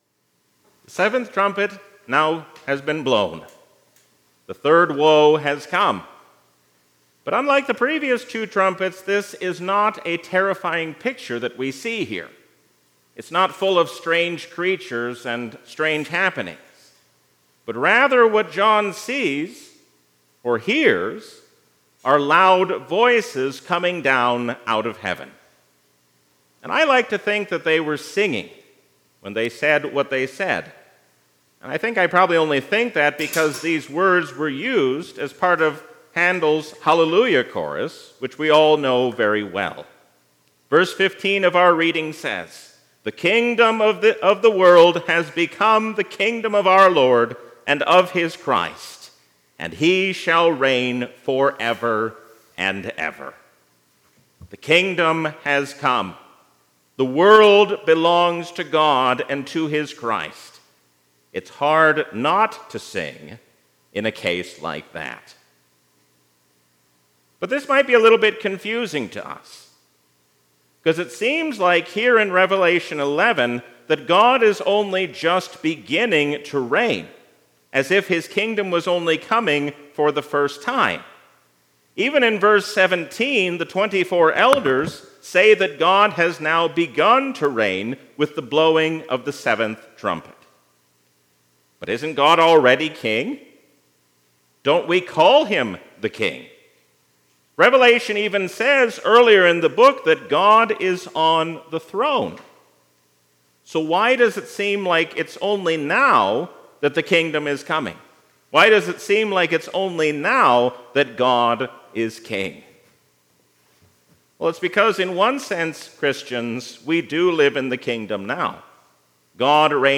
A sermon from the season "Trinity 2023."